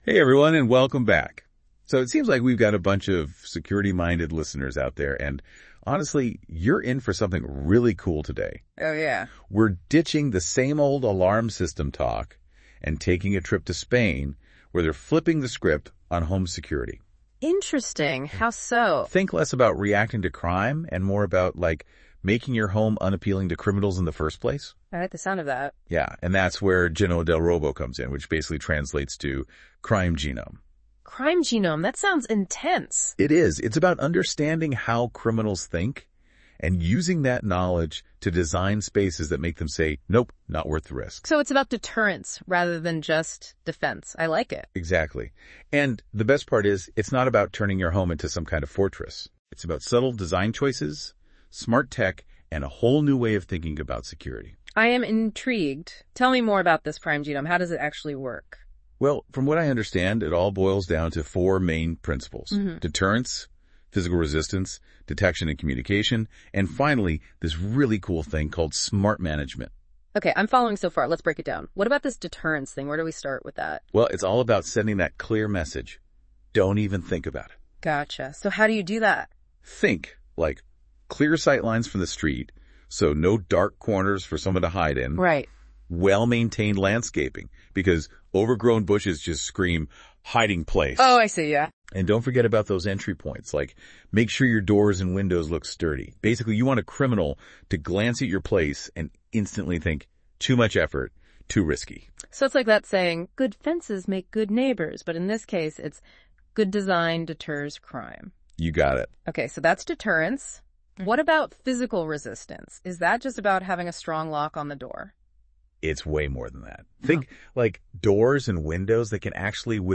It’s clear, engaging, and provides insights into Luxury Home Security by making even the most technical concepts accessible.